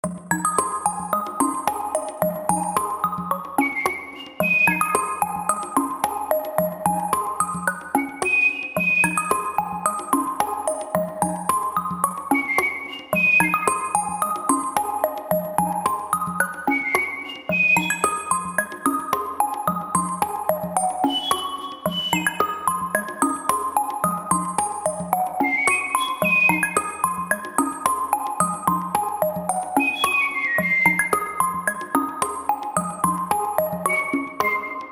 zuk-ping-pong_24601.mp3